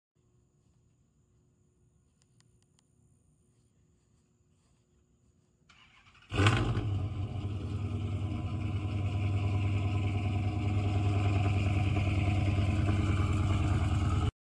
🔥 Chrysler 300C SRT 5.7 HEMI – Cold Start Rumble 🔊 That iconic HEMI V8 wakes with a deep, commanding growl.